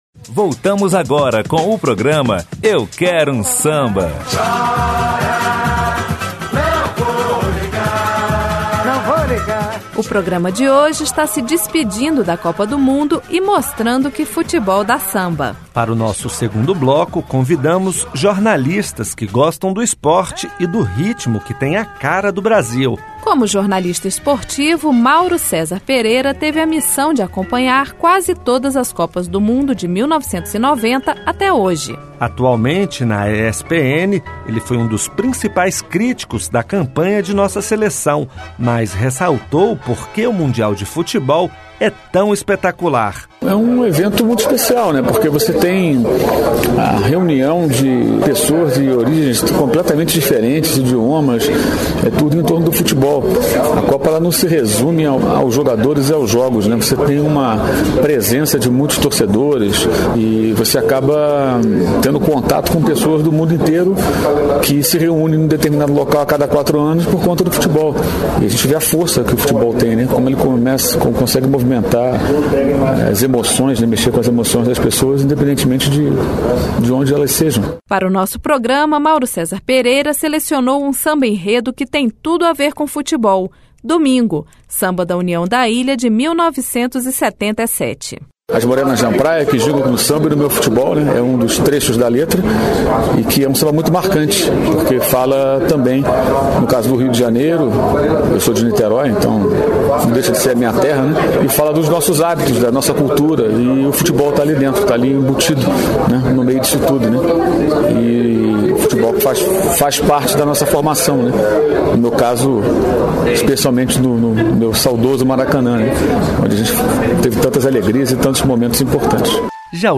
samba enredo